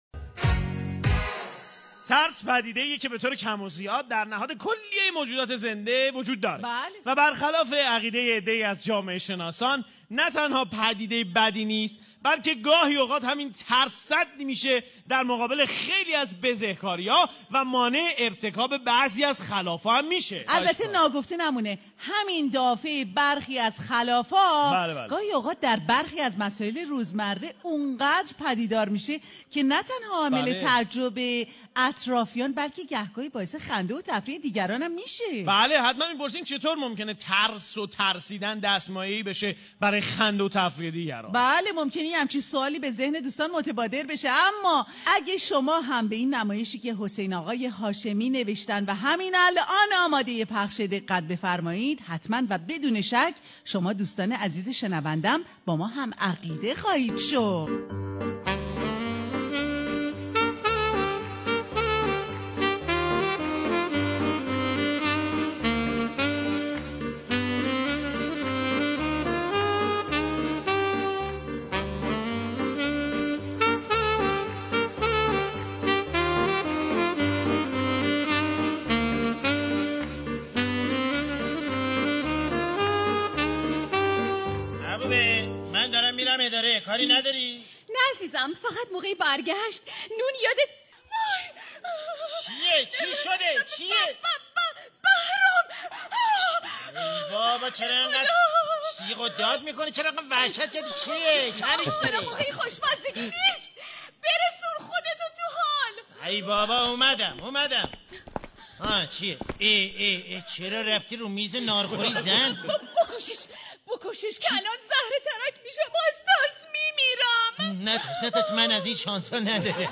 برنامه طنز جمعه ایرانی هر جمعه ساعت 9 تا 11:30 از رادیو ایران
برنامه طنز رادیو ایران